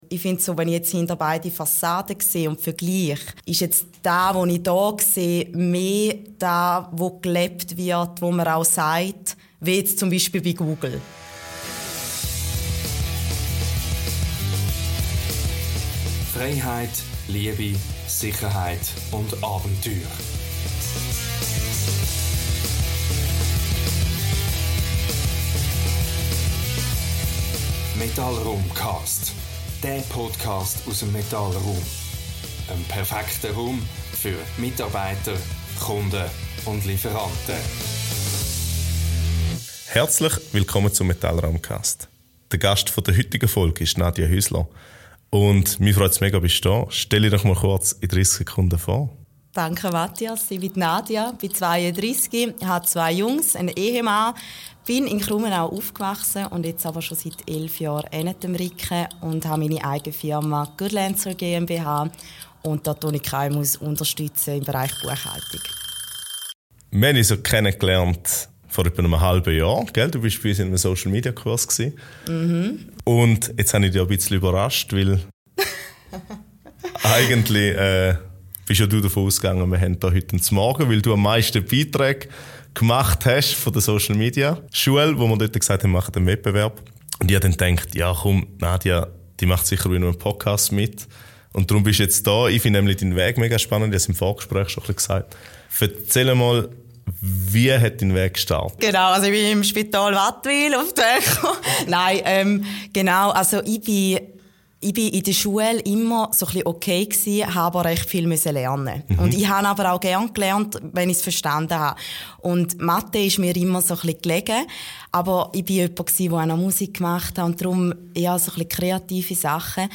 Ein inspirierendes Gespräch über Unternehmertum, Digitalisierung und Selbstverwirklichung!